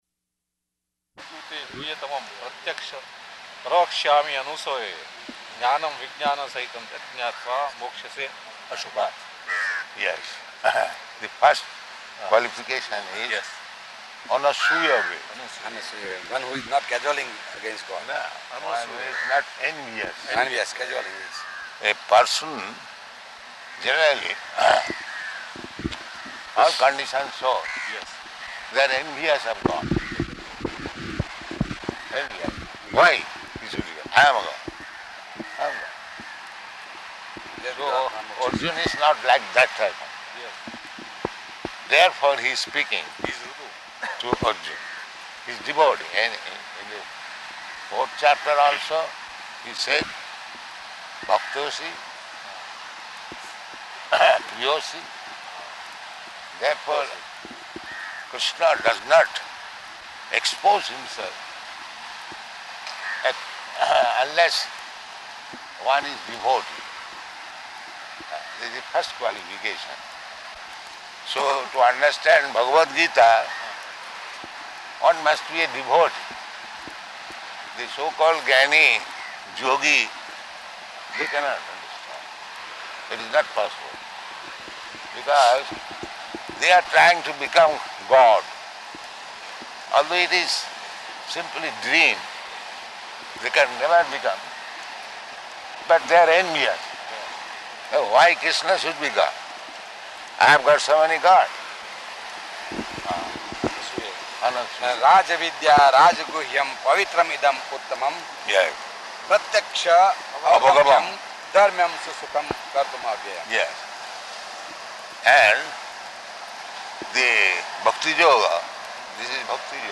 Type: Walk
Location: Bombay